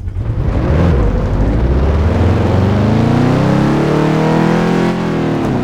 Index of /server/sound/vehicles/lwcars/dodge_daytona